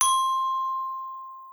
Glockenspiel.wav